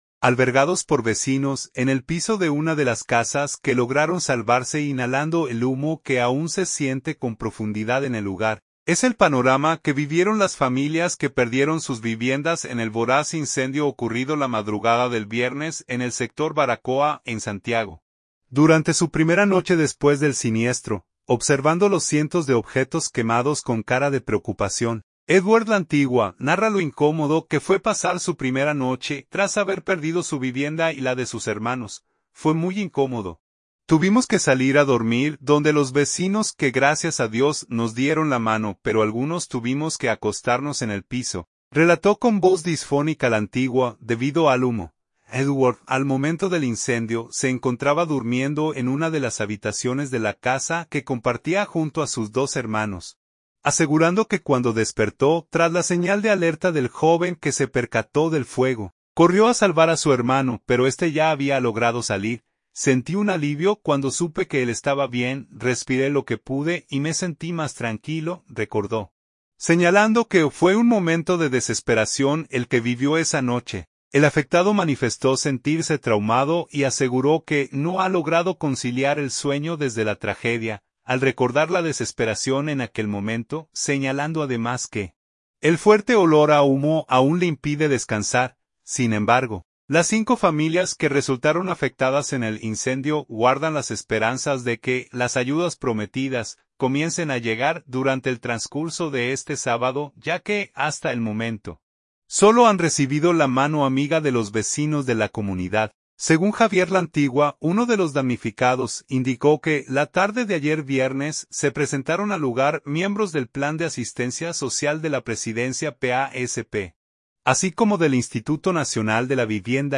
relató con voz disfónica